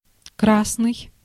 Ääntäminen
IPA: [ˈvak.ɛr]